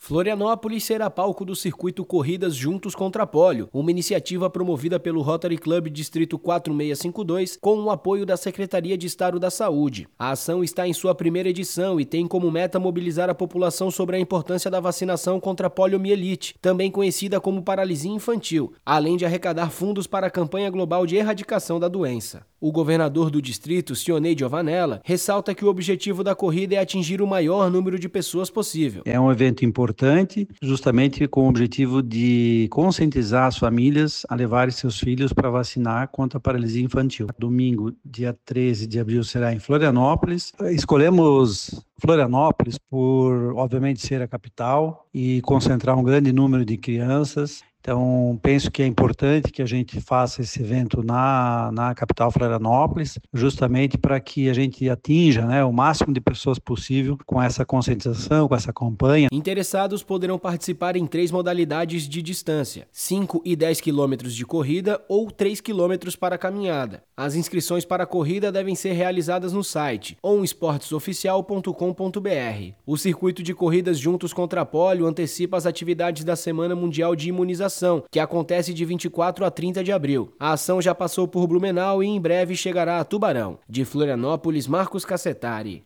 BOLETIM – Florianópolis se une na luta contra a poliomielite com corrida solidária